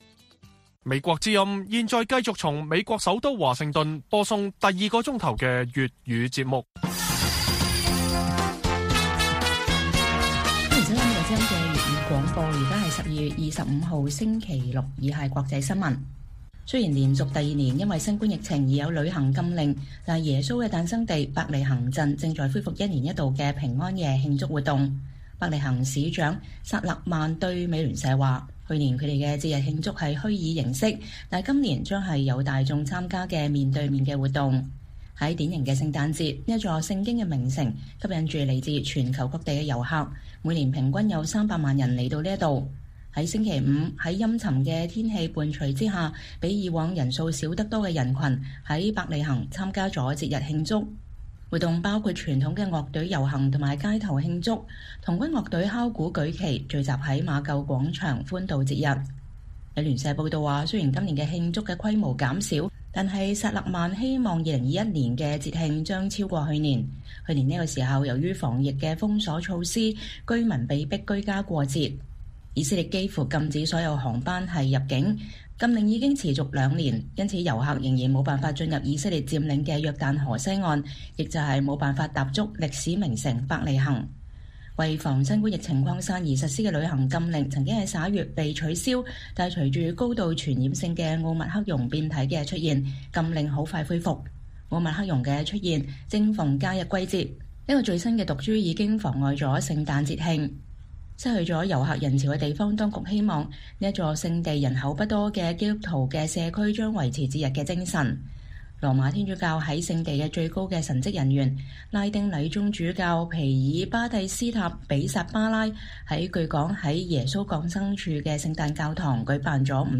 粵語新聞 晚上10-11點: “國殤之柱”在香港倒下 將在更多地方重新站立